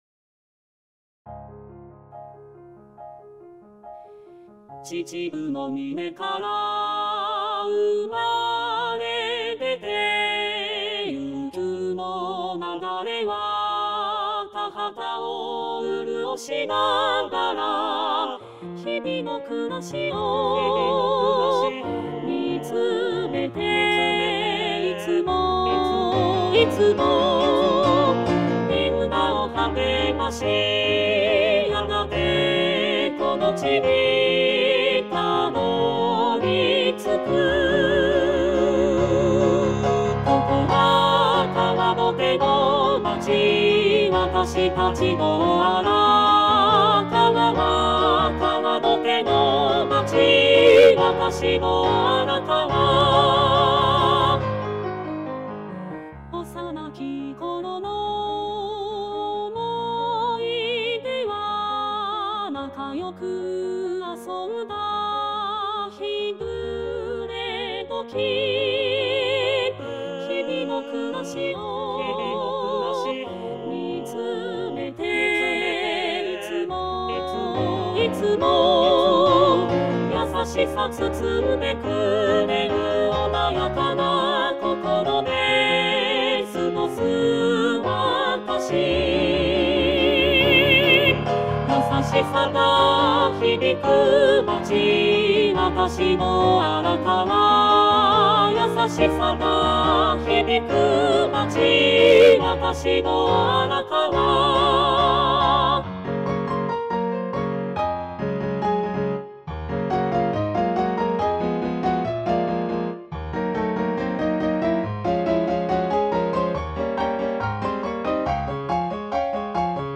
●パート単独音源　　　■Rchソプラノ、Lch、アルト、テノール、バス
hibinokurasi_bassueall.mp3